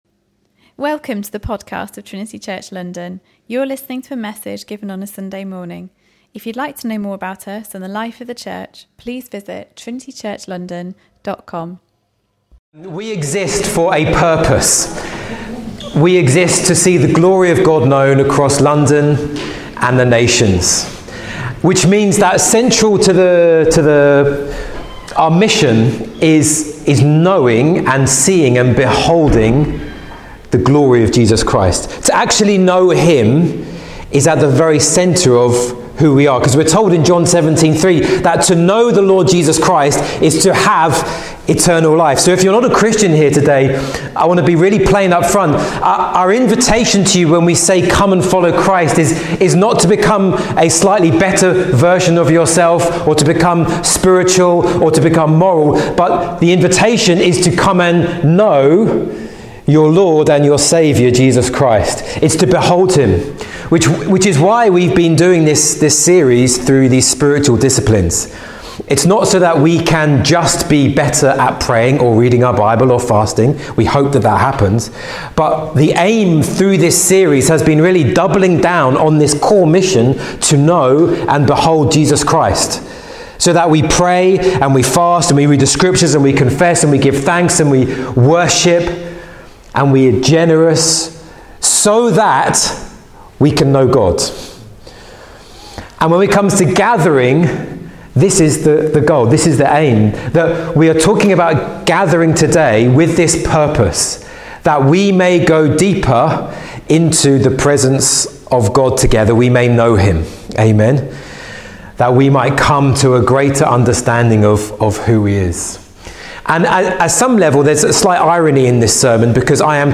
We cannot separate our relationship with Jesus from our relationship with the church. In this sermon